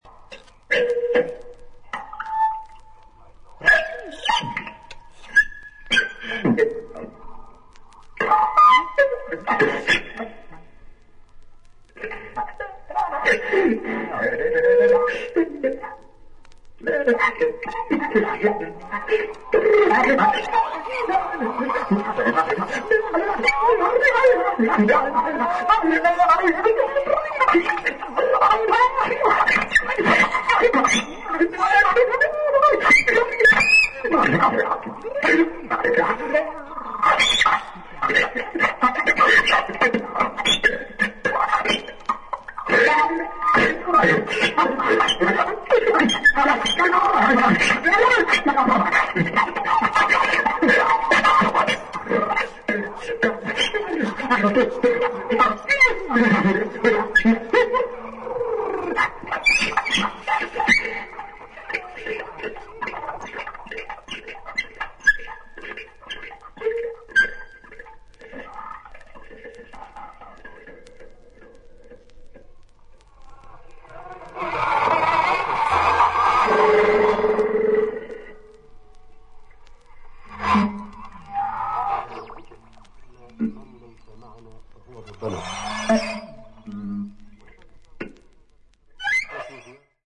短波受信機を用いて演奏される作品